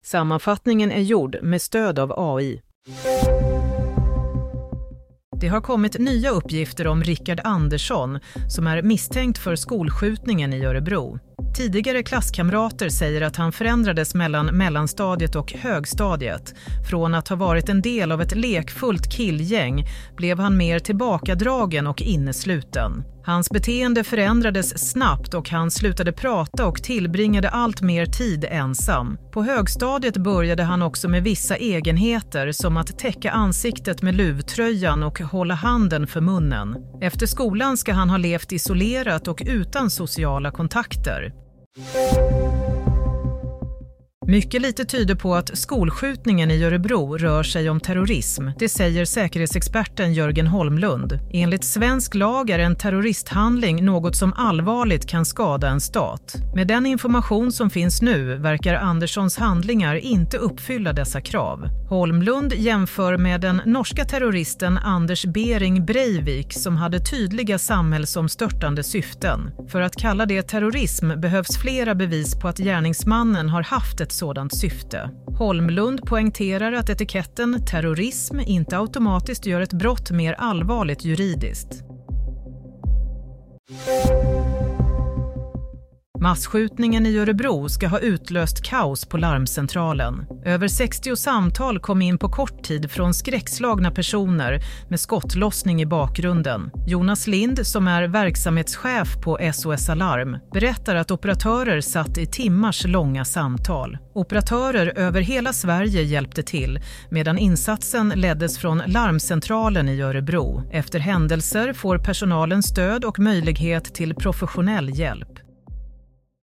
Nyhetssammanfattning - 5 februari 22:00